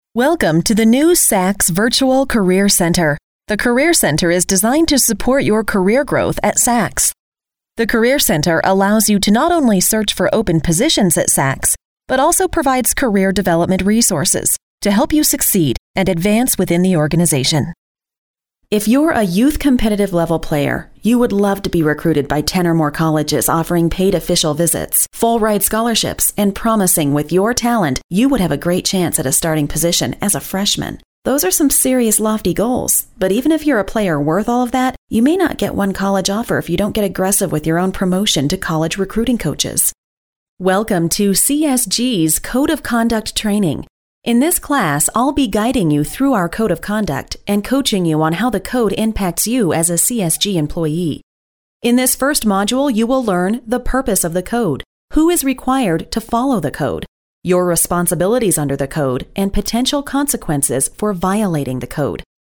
American English Voice Over artist
Sprechprobe: eLearning (Muttersprache):